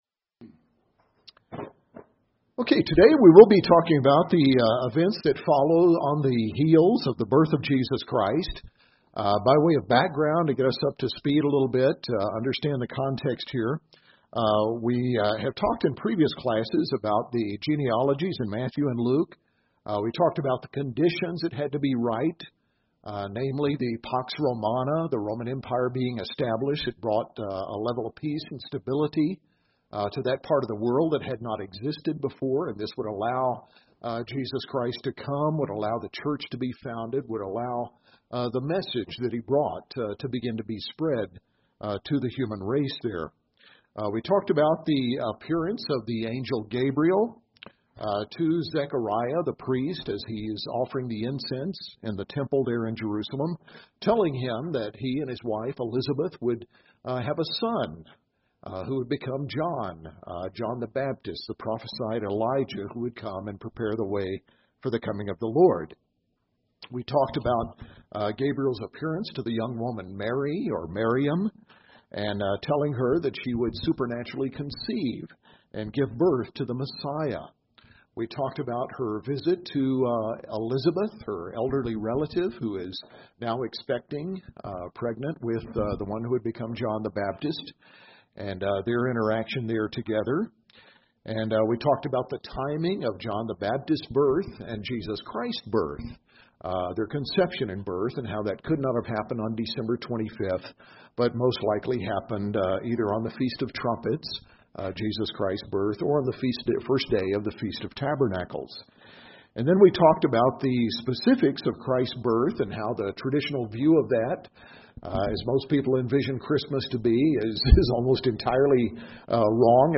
In this class we cover Matthew 2:1-23, the story of the visit of the wise men, Herod's murder of the infant boys of Bethlehem, and Joseph and Mary's sojourn to Egypt and return to settle in Galilee.